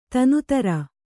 ♪ tanutara